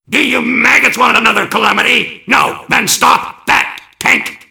mvm_tank_alerts05.mp3